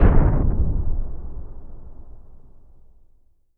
LC IMP SLAM 2.WAV